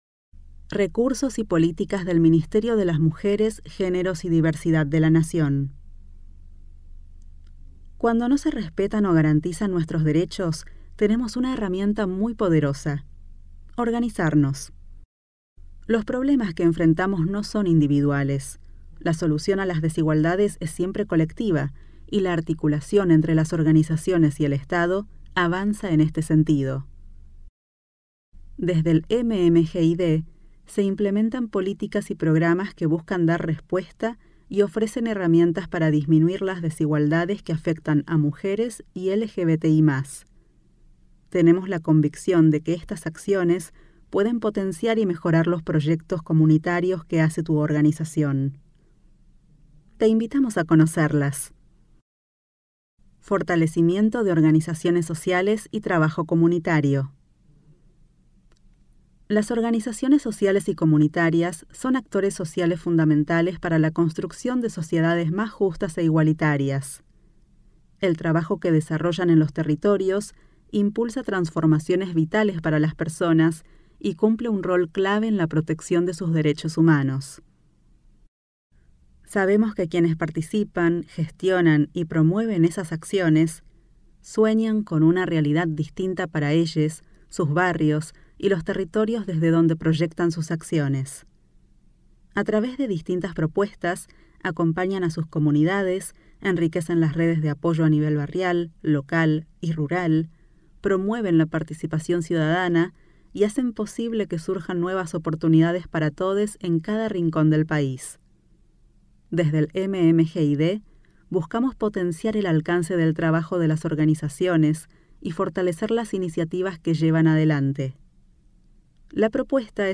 Audiolibro 03 - Guía de recursos - Recursos y políticas del MMGyDdbfe.mp3